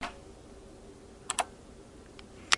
房子周围的随机声音" 鼠标点击
描述：单击无线计算机鼠标。在2017年5月24日在我家录制了索尼icdpx 333录音机。无线鼠标是罗技USB鼠标。